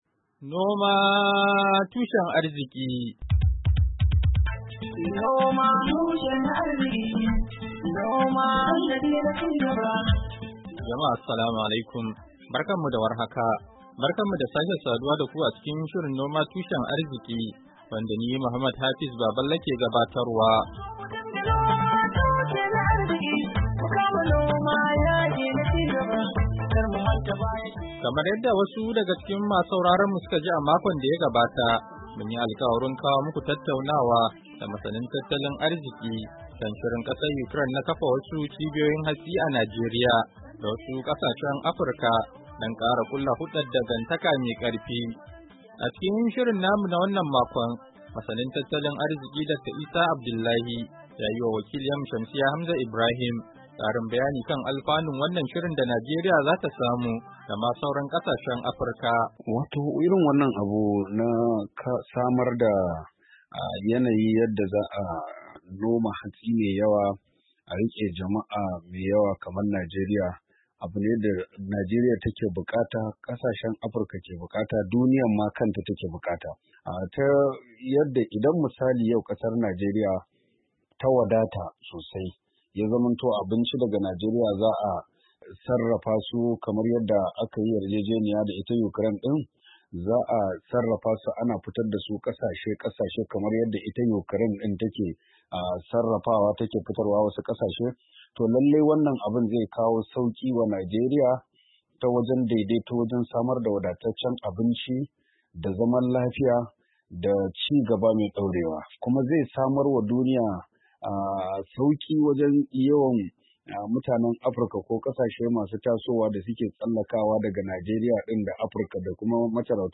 Shirin na wannan makon, ya tattauna da masanin tattalin arziki da dan kasuwa kan shirin kasar Ukraine na kafa cibiyoyin hatsi a Najeriya.